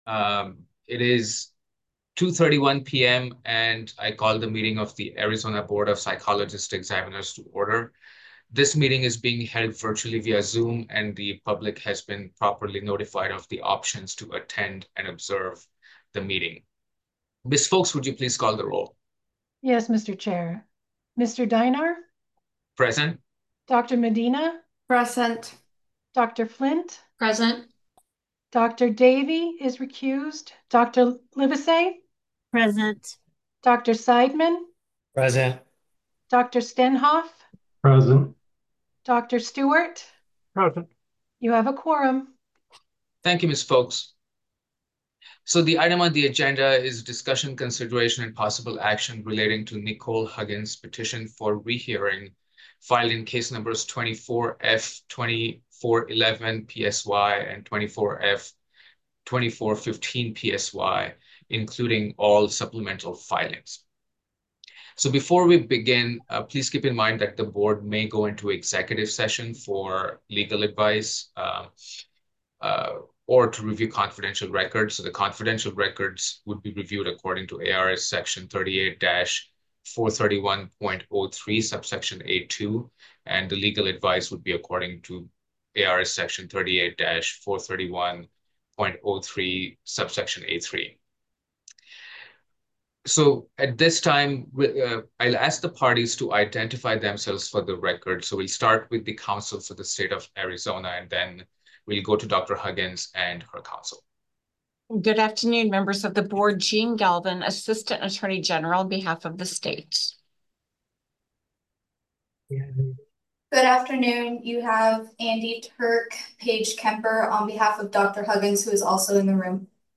Members will participate via Zoom